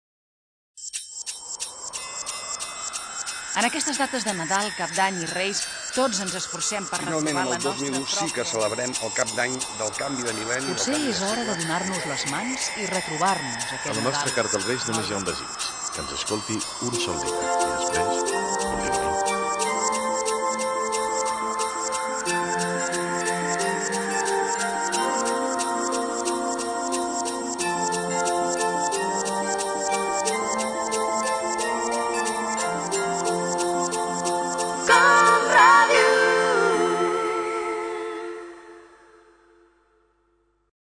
Felicitació de Nadal